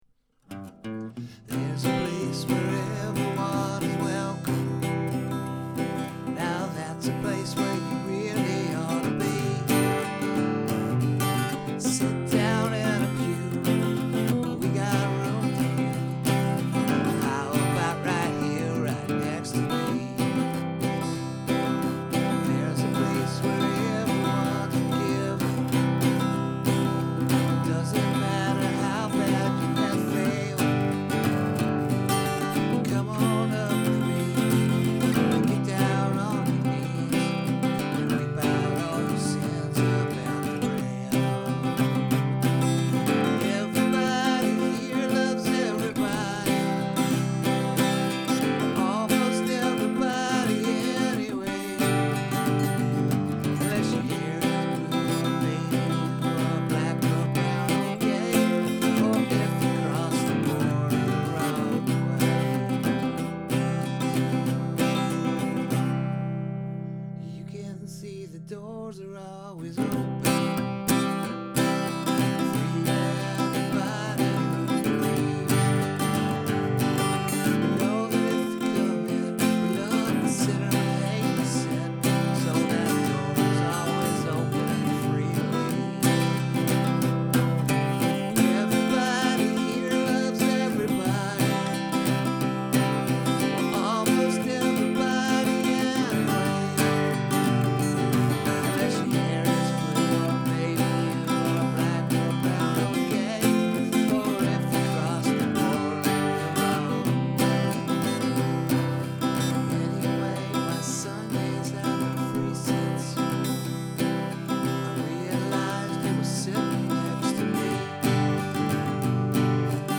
Love the jaunty rhythm and chorus is great!